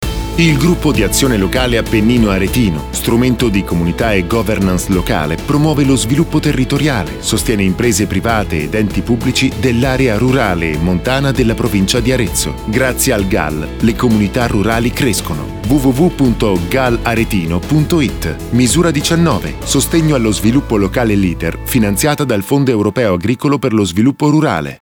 Radio Italia 5 spot n. 1